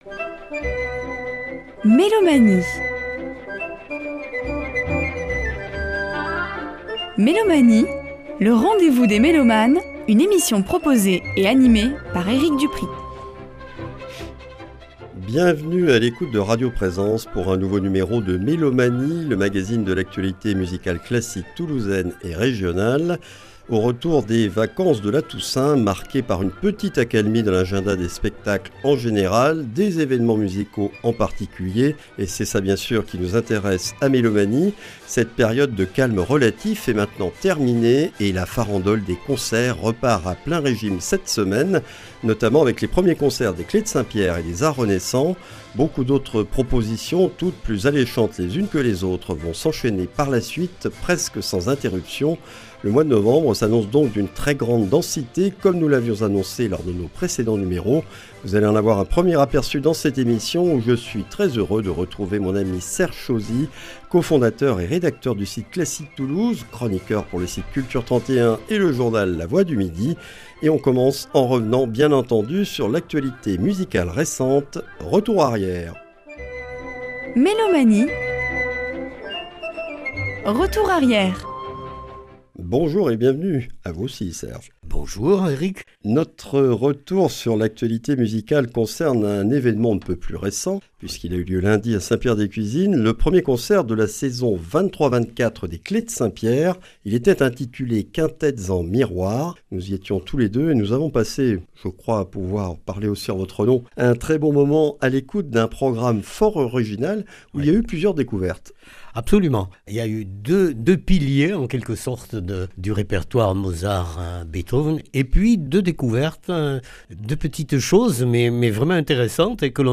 Mélomanie(s) et ses chroniqueurs présentent l’actualité musicale classique de notre région.